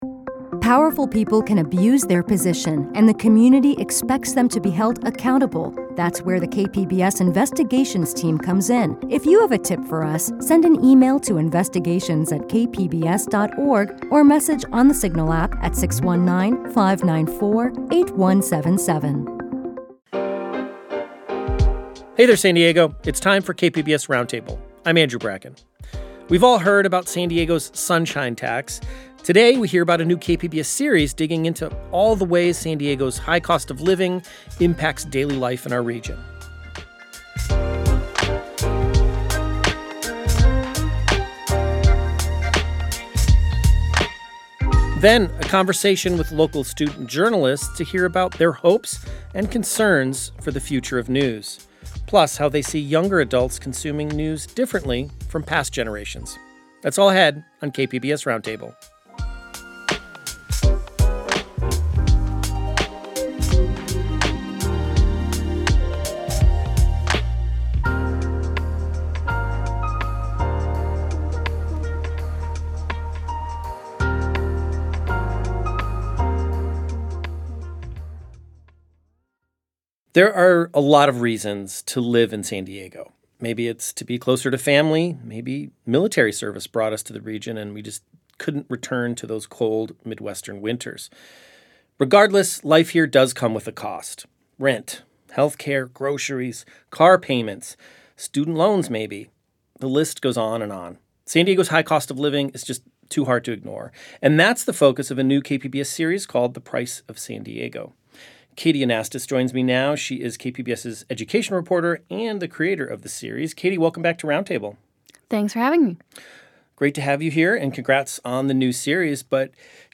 On KPBS Roundtable this week, we hear from some of San Diego's student journalists about their outlook on the current media landscape, as they start their journalism careers during a precarious time for the industry.